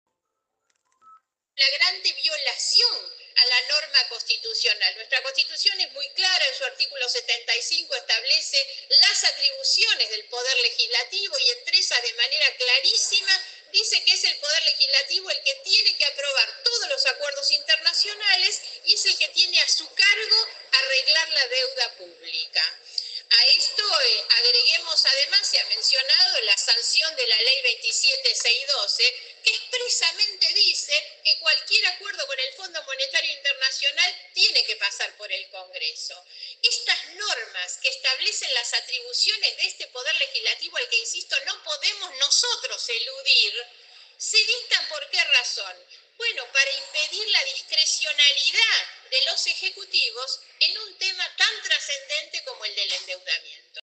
Durante la sesión especial en la Cámara de Diputados, Stolbizer consideró que el presidente Javier Milei decidió «gobernar al margen de la ley» al enviar el acuerdo por decreto en lugar de un proyecto de ley.